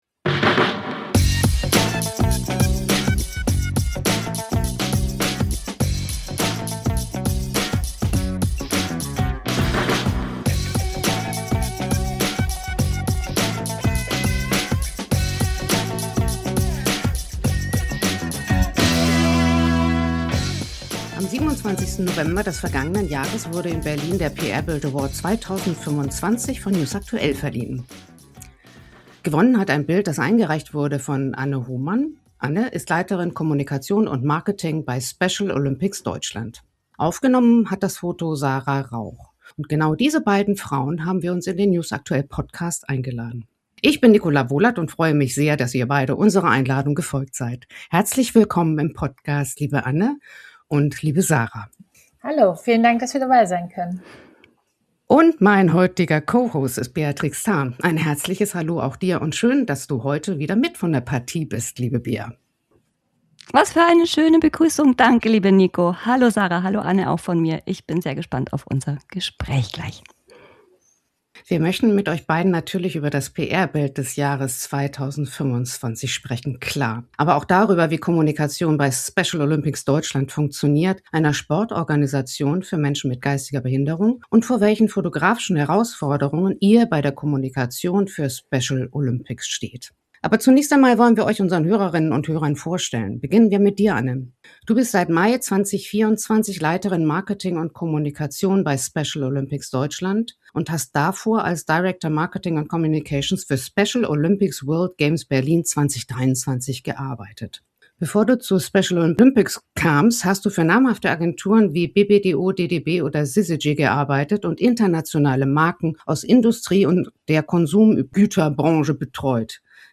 Beide sind in der aktuellen Folge des news aktuell Podcast zu Gast. Mit ihnen sprechen wir über die Entstehung des Bildes und über die Herausforderungen bei der Ausrichtung nationaler und internationaler Wettkämpfe für Athletinnen und Athleten mit geistigen Einschränkungen.